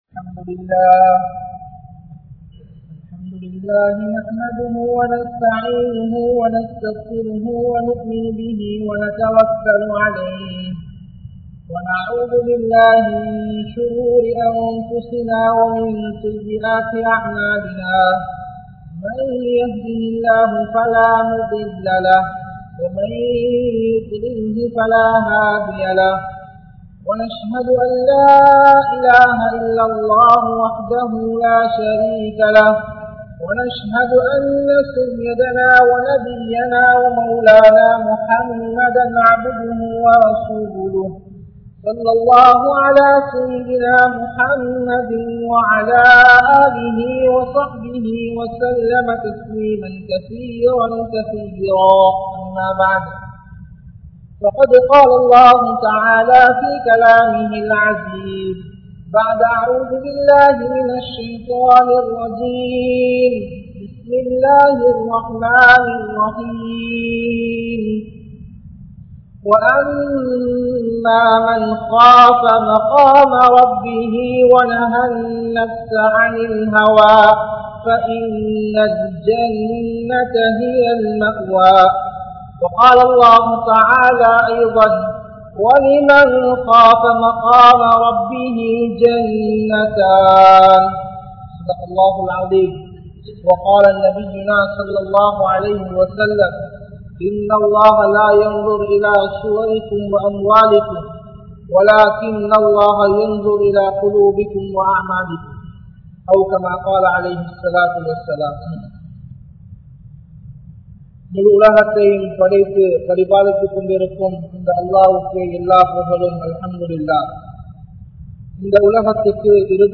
Vaalkaiyai Alikkum Paavangal (வாழ்க்கையை அழிக்கும் பாவங்கள்) | Audio Bayans | All Ceylon Muslim Youth Community | Addalaichenai